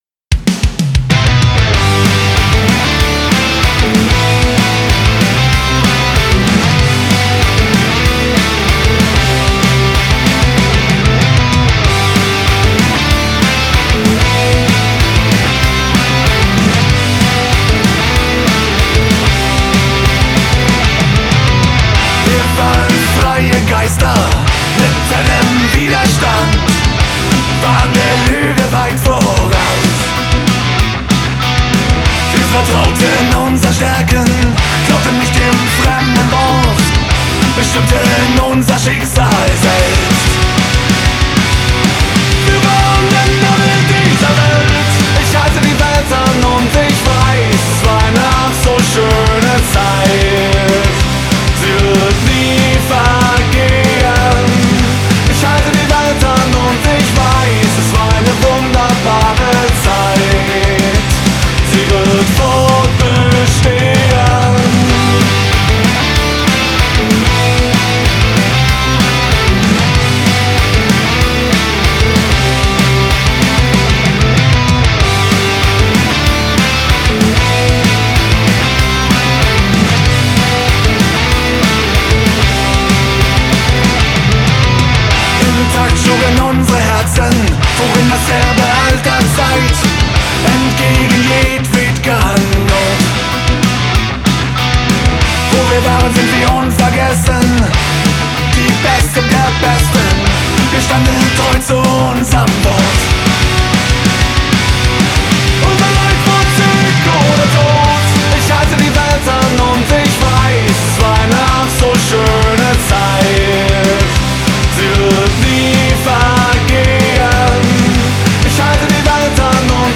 ist ein lockere Poprock Nummer die man
Gesang und Instrumentbeherrschung ist sehr gut und Modern!